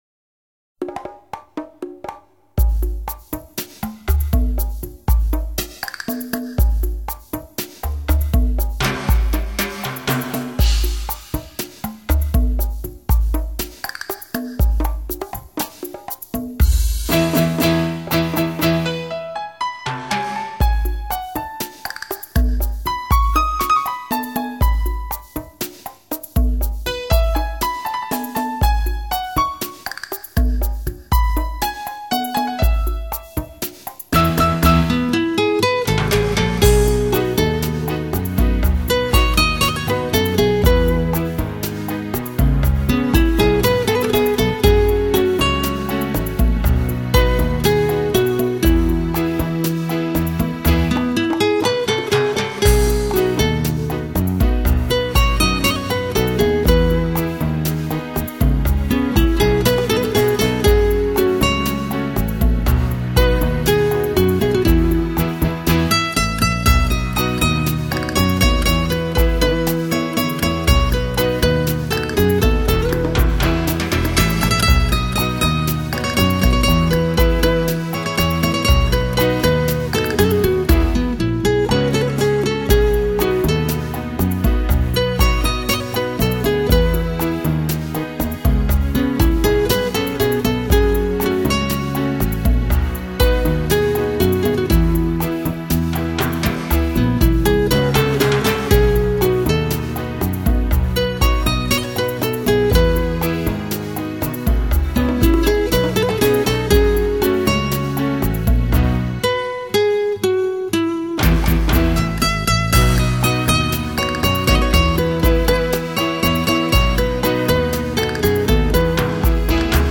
吉他独奏